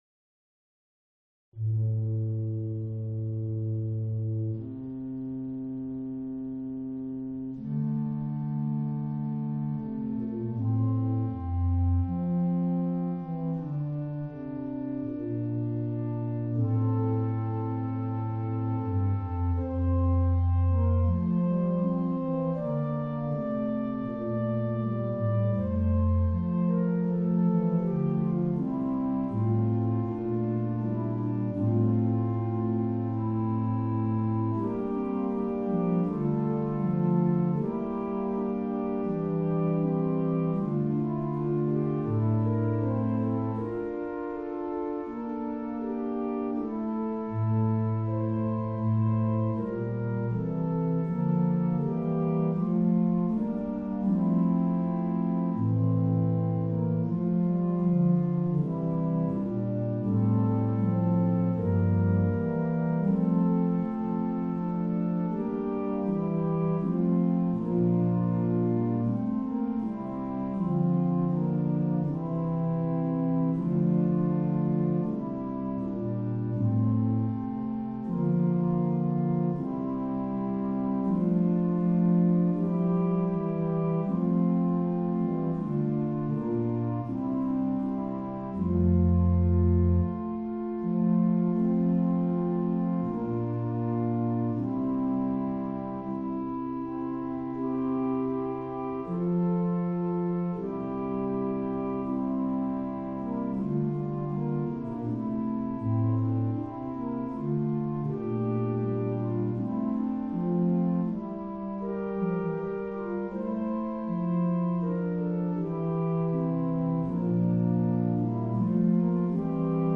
To audition background music for the Introductory Prayers of The Chaplet of the Divine Mercy (the short Prayers specific to The Chaplet of the Divine Mercy, The Pater Noster, The Ave Maria, and The Apostles Creed), click the ▶ button to listen to an organ setting of the composition Ave Verum Corpus by the Burgundian composer Josquin des Prez (1455-1521), or play the music in a New Window